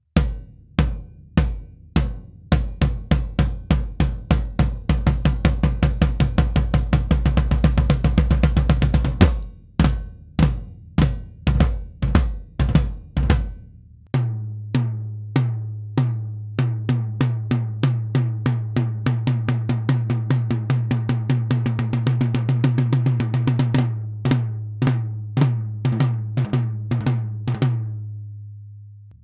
Schlagfell: Amba coated, Reso CS Black dot. Abstand zur Trommel: rund 10 cm
Beyerdynamics M 88 TG
m88, m201 und pro25 haben richtig Druck beim tiefen Tom, wobei mir das pro25 da schon zu viel dröhnt.